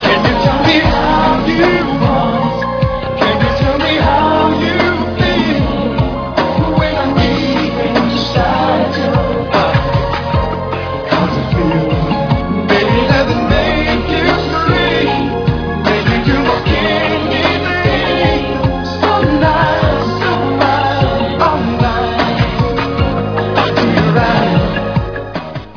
and synthesizers